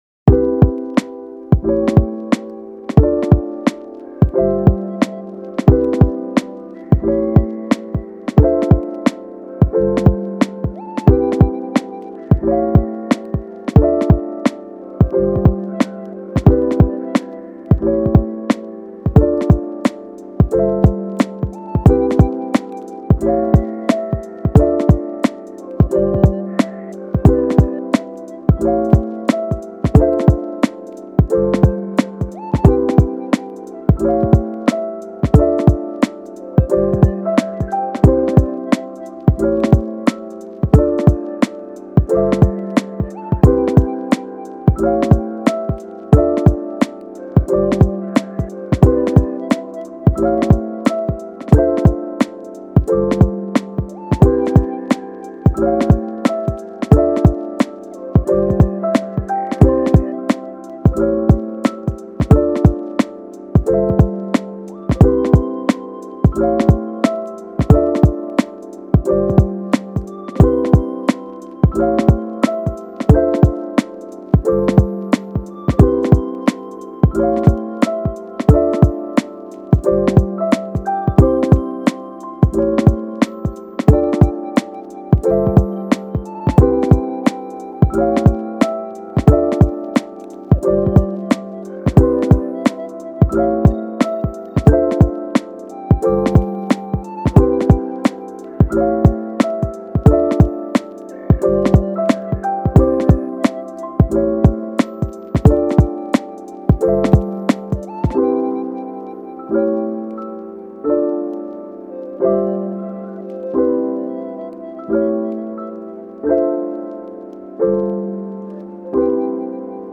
カフェミュージック チル・穏やか フリーBGM